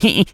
Animal_Impersonations
rabbit_squeak_angry_01.wav